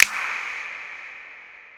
WeekndClap.wav